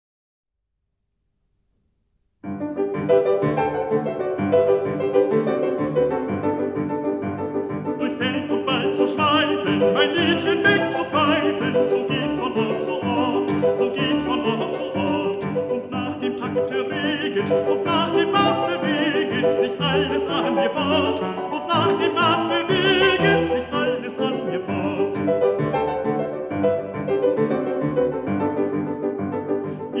2 Solo Vocal Der Musensohn